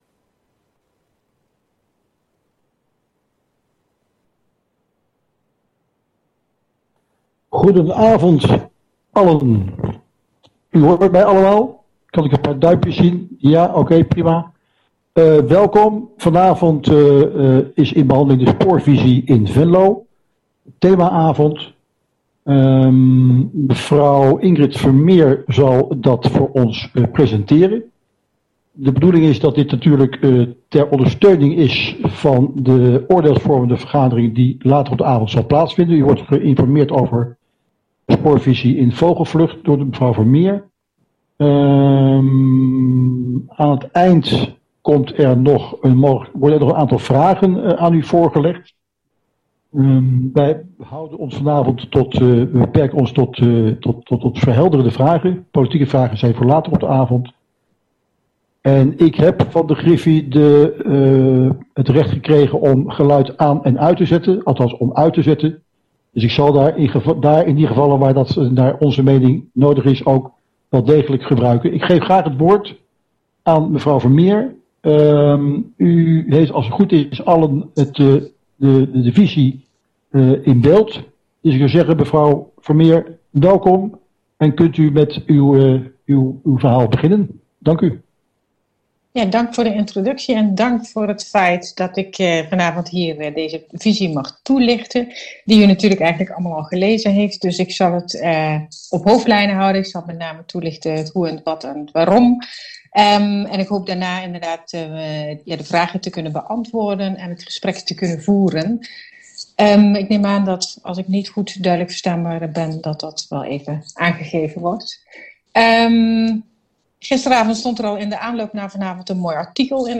Oordeelsvormende raadsvergadering 11 november 2020 19:00:00, Gemeente Venlo
Sessievoorzitter: Harro Schroeder Portefeuillehouder(s): burgemeester Antoin Scholten en wethouder Erwin Boom